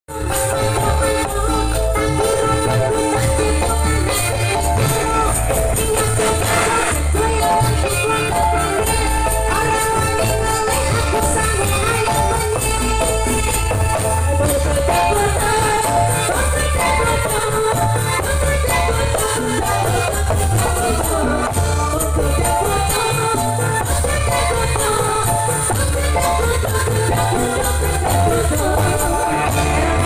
koploan